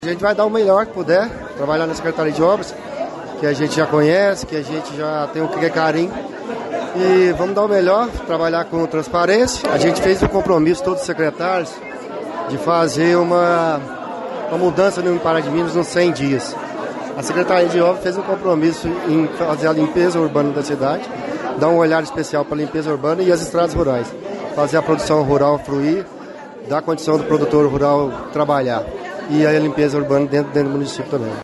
O secretário de Obras, André Lara Amaral, conversou com a nossa reportagem e destacou os objetivos para a pasta neste início de gestão: